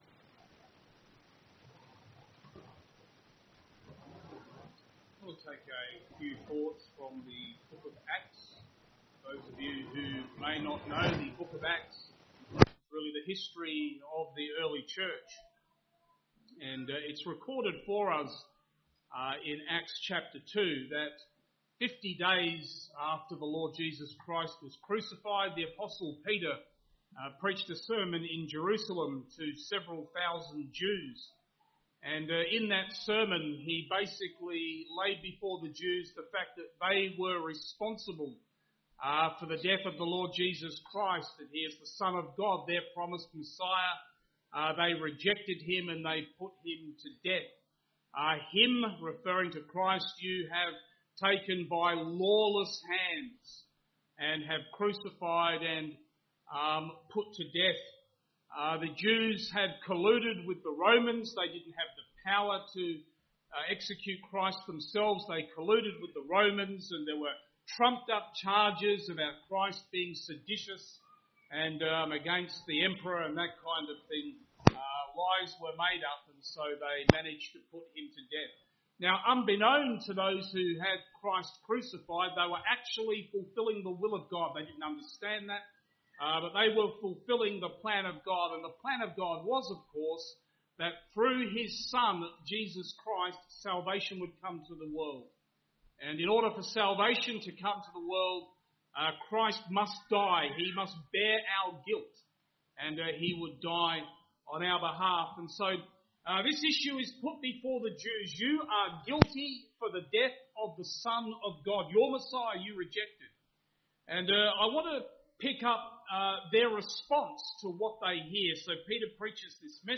Baptismal service – Acts 2:37-41